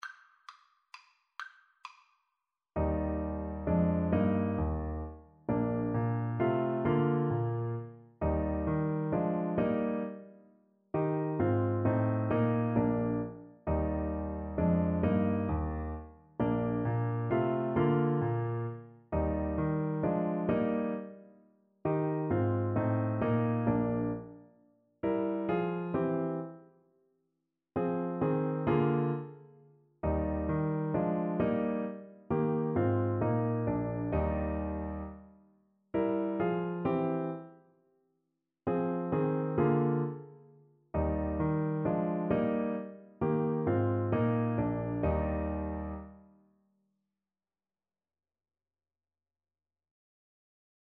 • Unlimited playalong tracks
= 132 Allegro (View more music marked Allegro)
3/4 (View more 3/4 Music)
Classical (View more Classical Saxophone Music)